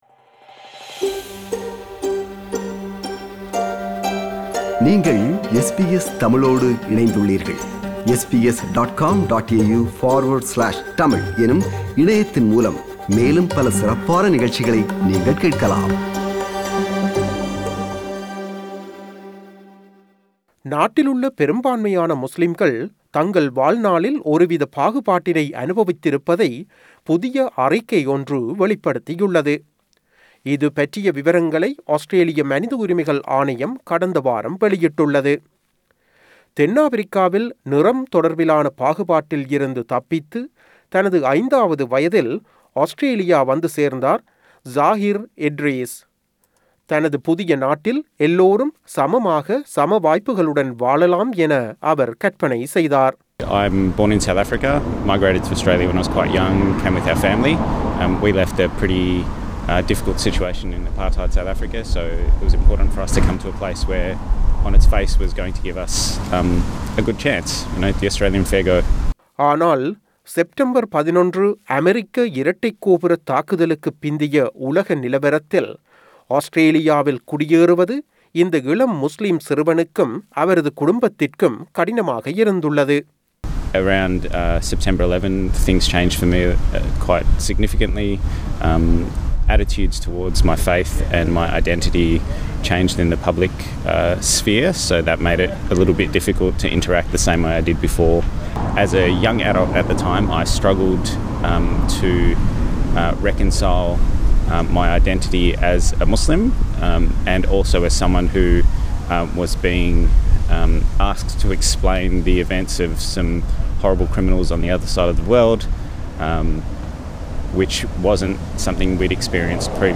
செய்தி விவரணத்தை தமிழில் தருகிறார்